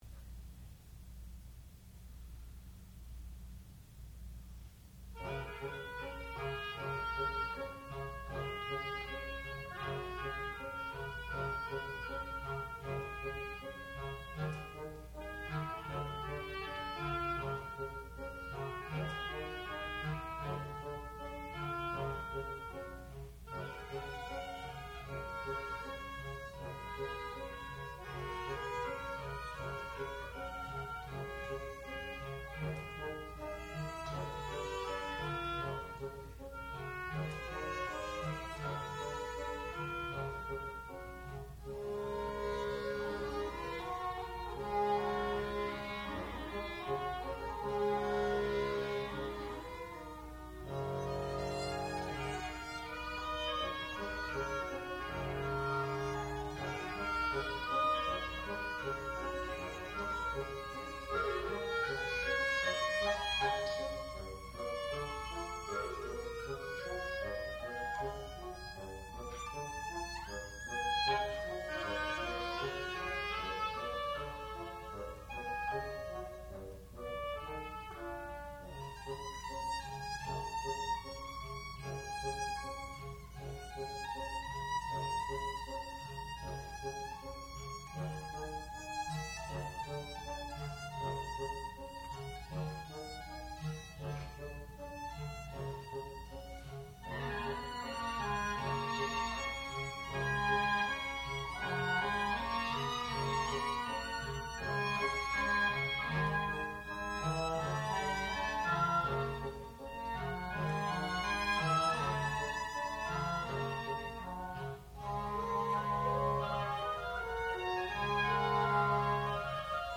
sound recording-musical
classical music
English horn
oboe
bassoon
Graduate Recital